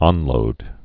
(ŏnlōd, ôn-)